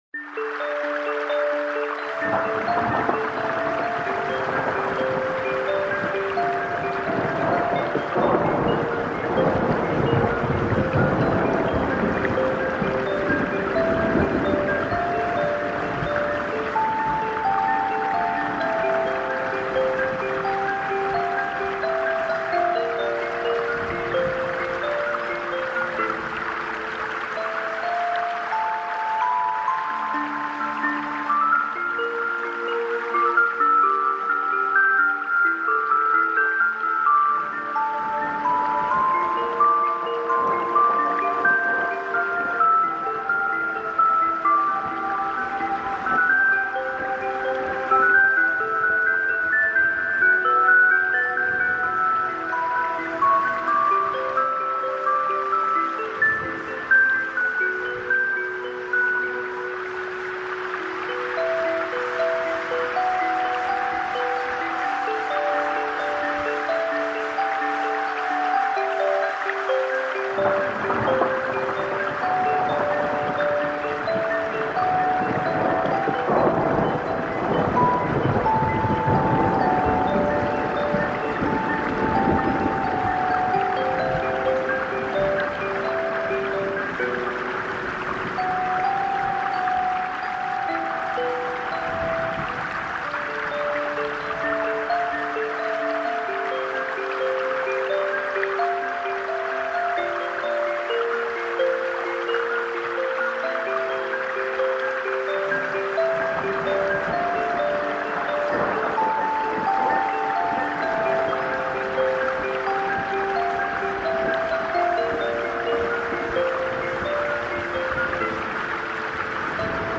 HEILENDE SANFTHEIT: Asien-Windhauch mit Natur voller Zartheit